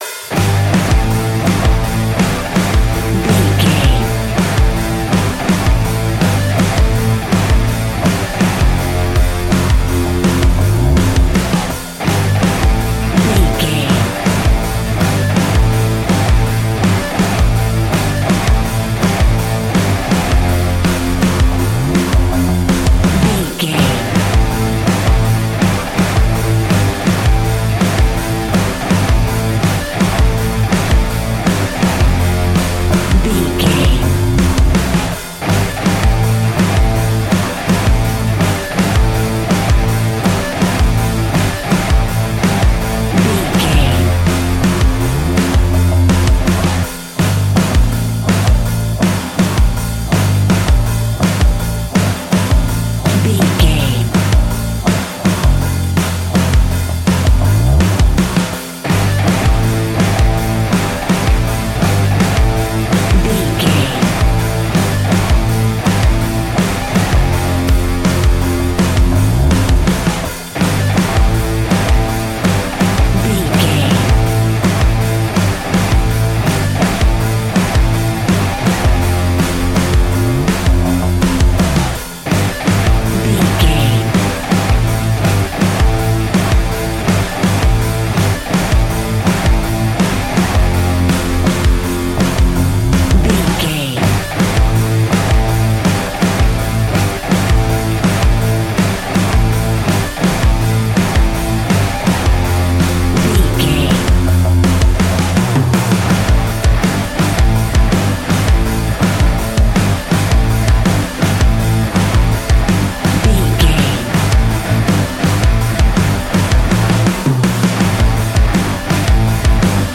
Ionian/Major
hard rock
guitars
heavy metal
instrumentals